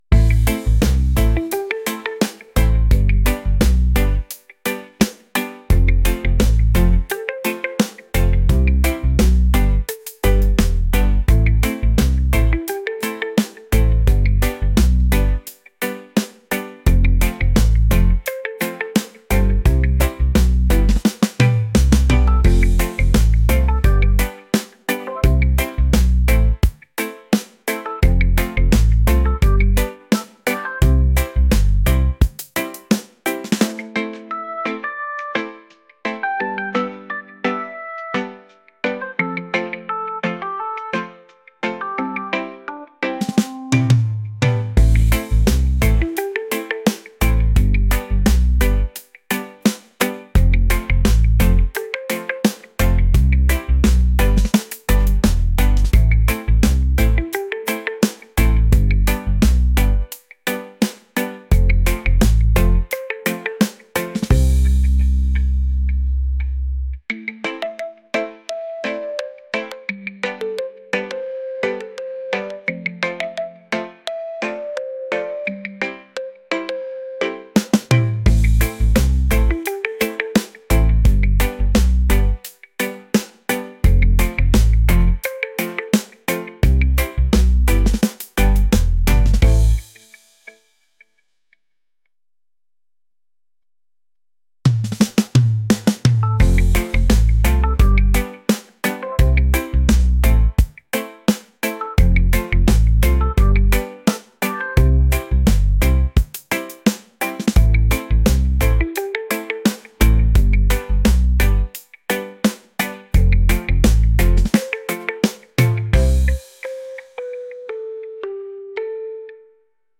laid-back | reggae